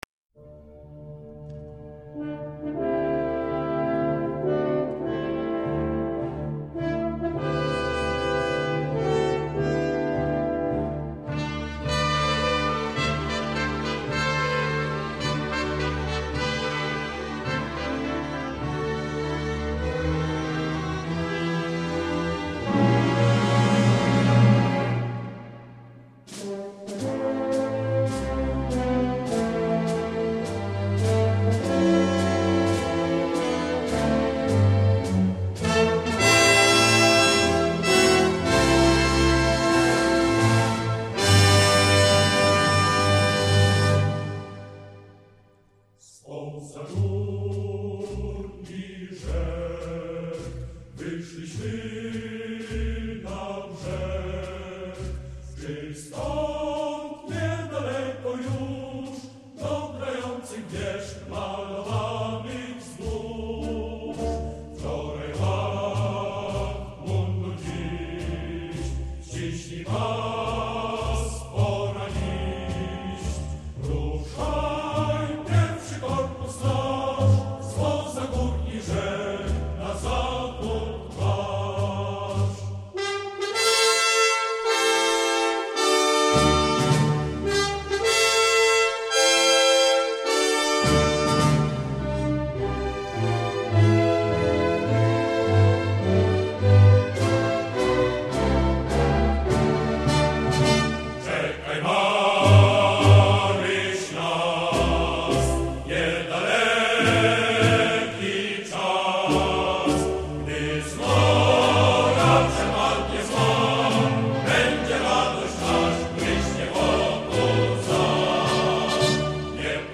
Марши